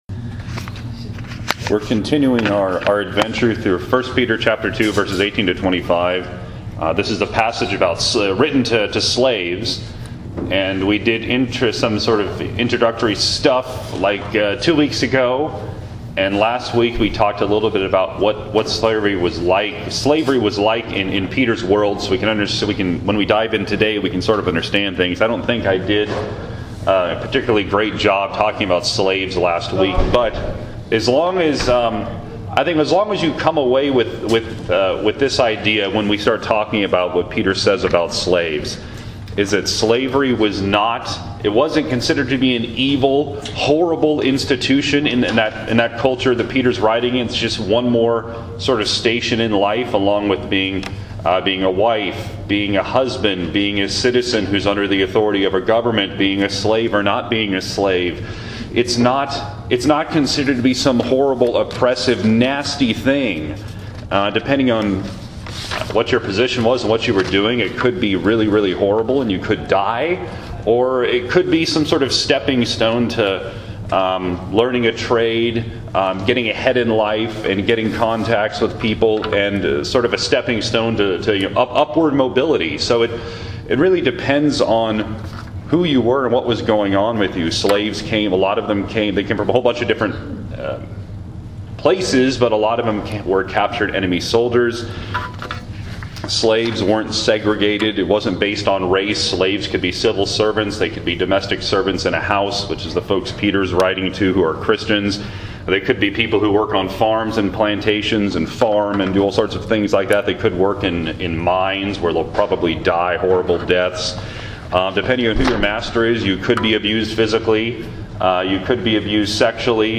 Today, in Sunday School, we continued looking at 1 Peter 2:18-25, and considering what it means: